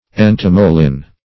entomolin - definition of entomolin - synonyms, pronunciation, spelling from Free Dictionary Search Result for " entomolin" : The Collaborative International Dictionary of English v.0.48: Entomolin \En*tom"o*lin\, n. [Gr.
entomolin.mp3